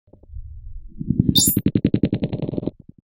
UI_SFX_Pack_61_48.wav